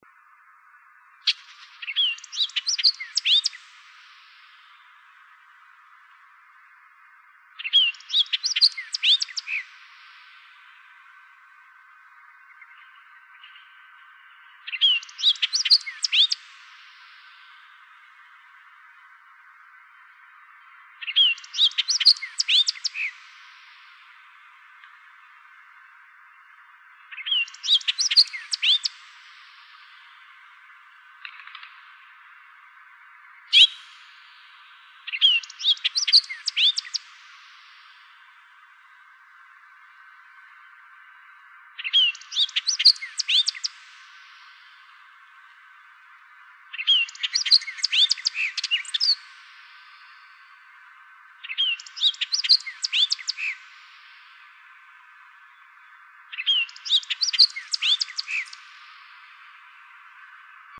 hoodedoriole.wav